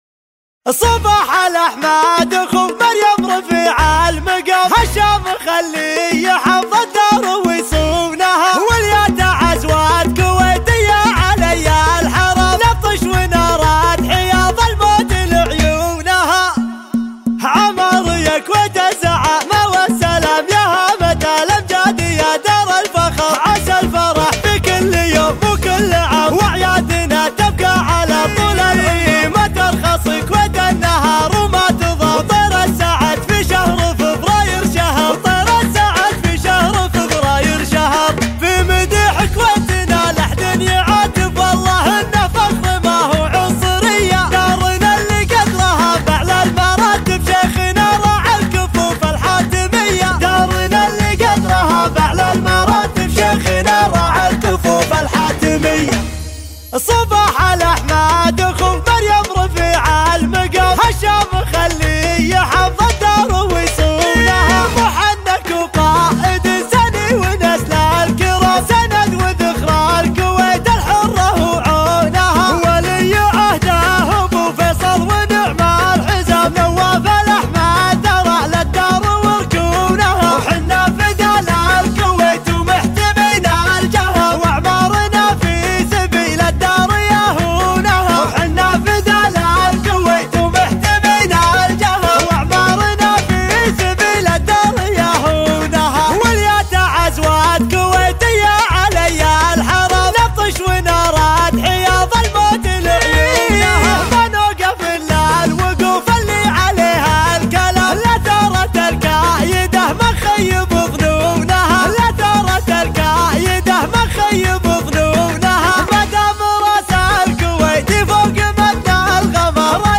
شيلة (وطنية)